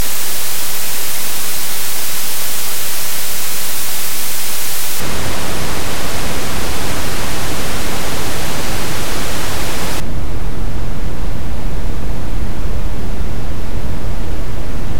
My pink noise generator
(first white,
then pink,
then red noise)
whitepinkrednoise.mp3